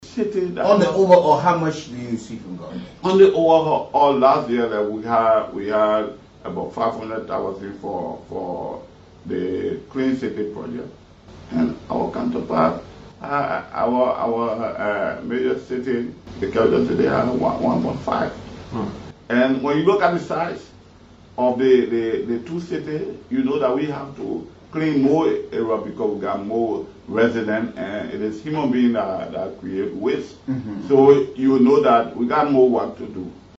On November 14, Paynesville City Mayor Robert Bestman claimed during a live interview on Kool FM that the 2024 national budget allotted about US$500,000 to Paynesville City Corporation (PCC) for the Clean City Project, while US$1.5 million was allocated to the Monrovia City Corporation (MCC) for the same project